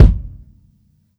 soft-hitwhistle.wav